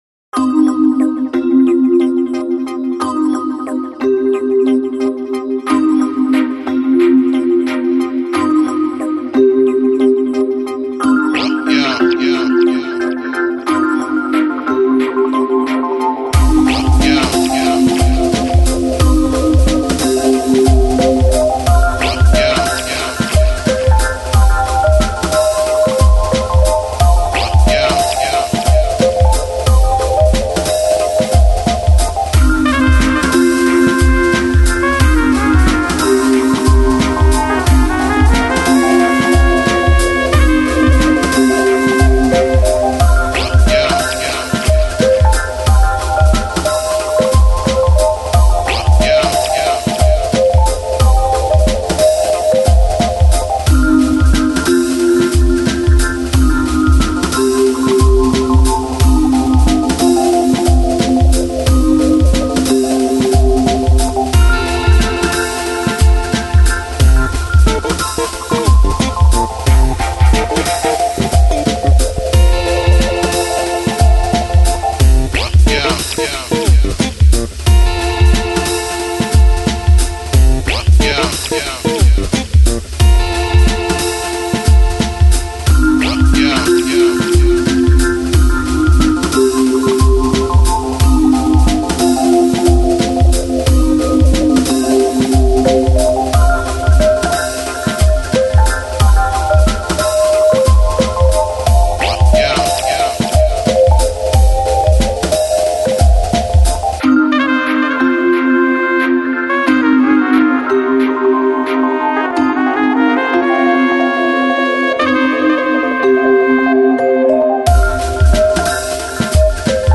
Жанр: Electronic, Chill House, Balearic, Downtempo